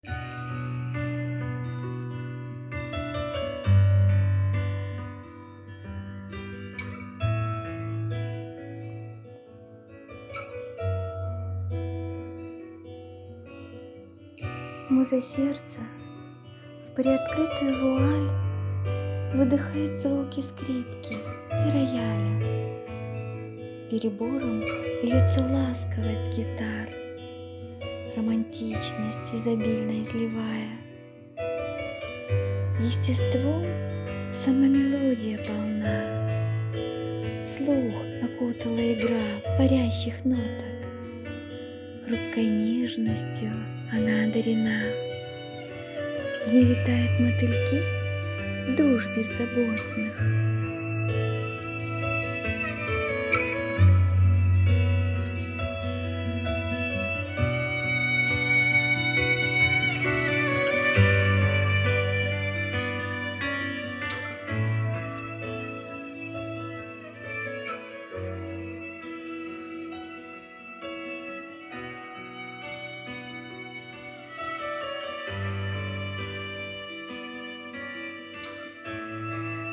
Голос казковий. 16 16 16
А вот с оформлением - вышло нежно и очень женственно!
Она успокаивает и возносит...
Умиротворяет своим спокойствием и гармоничностью с миром...